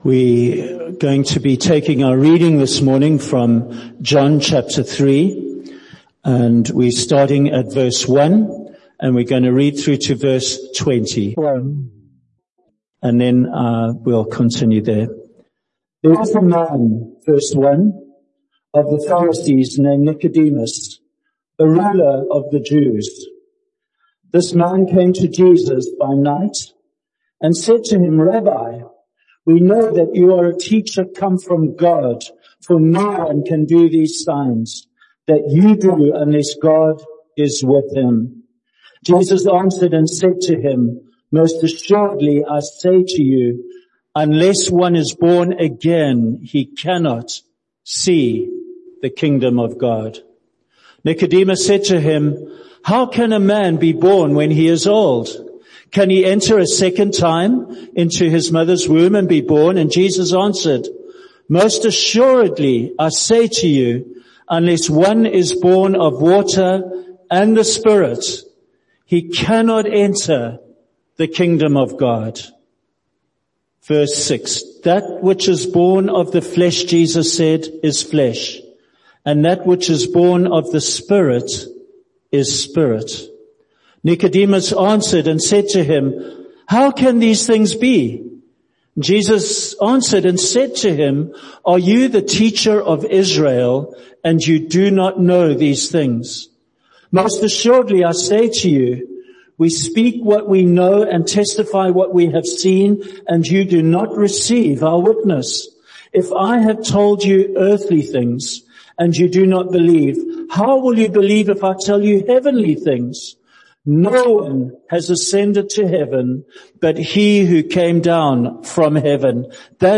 With a relaxing of the lock down rules during the Coronavirus crisis the church is able to run services again in the sanctuary, but with a limited number of worshippers.
Below is the recording of the sermon for this week.